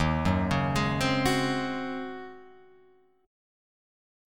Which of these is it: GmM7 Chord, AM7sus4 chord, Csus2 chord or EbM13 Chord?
EbM13 Chord